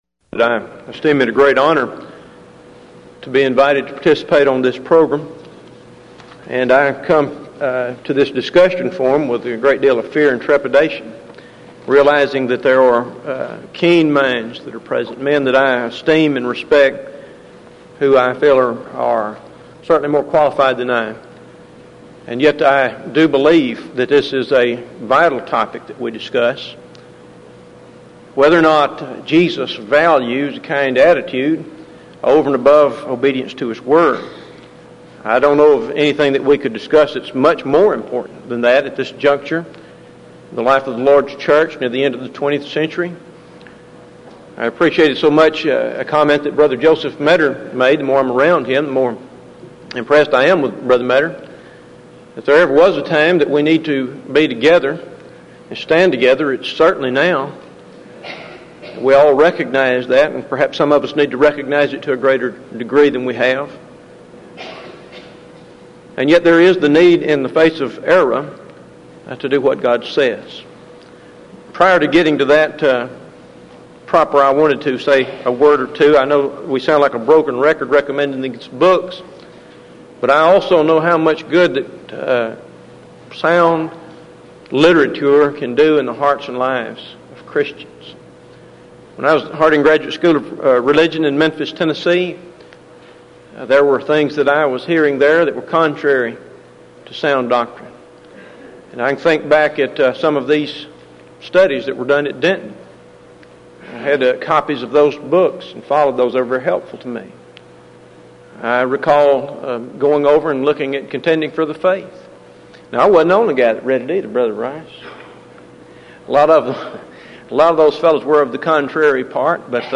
Event: 1995 Denton Lectures